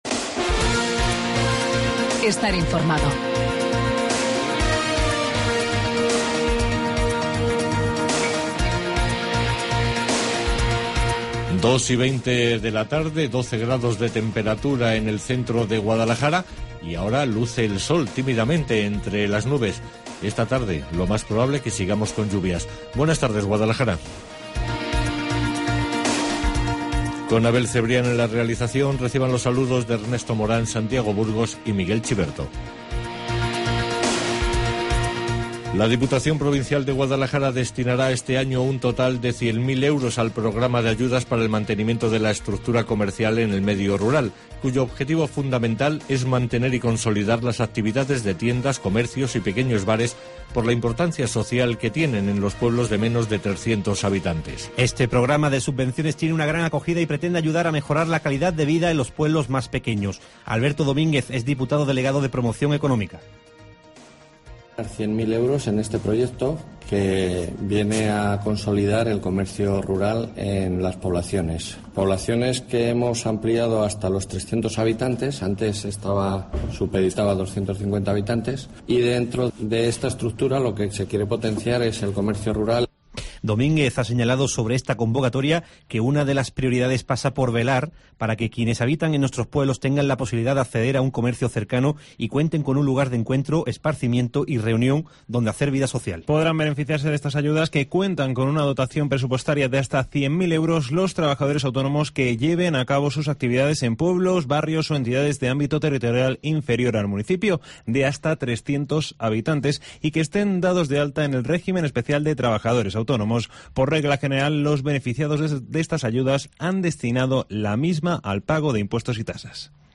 Informativo Guadalajara 4 de abril